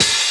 005_Hi-Hat 02 - No Man's Joint.wav